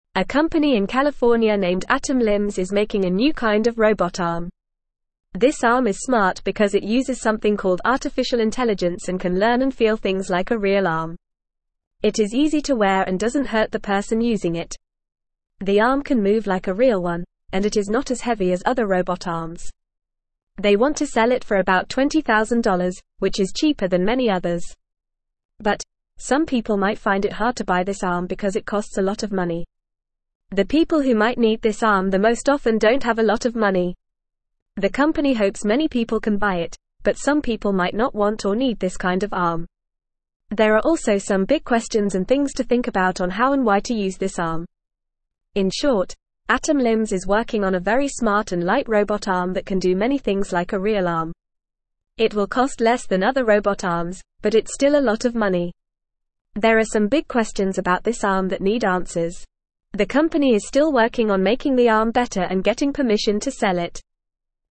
Fast
English-Newsroom-Lower-Intermediate-FAST-Reading-Special-Robot-Arm-Moves-like-a-Real-Arm.mp3